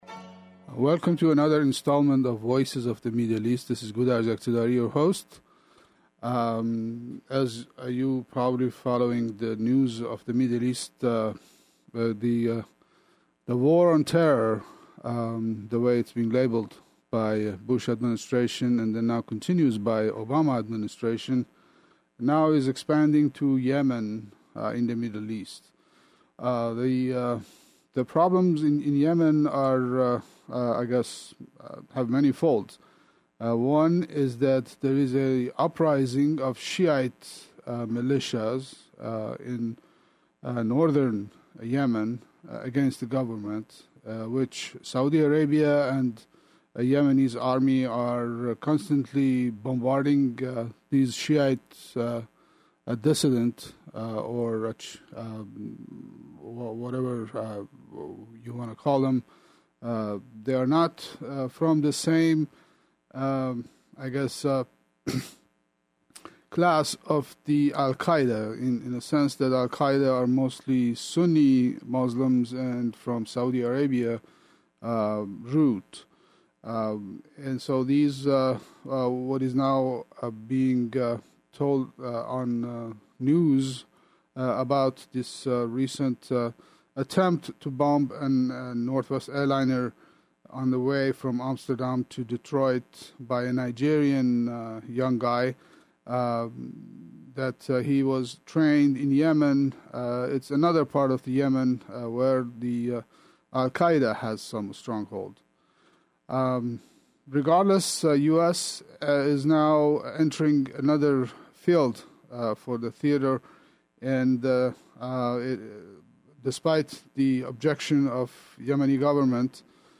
Two talks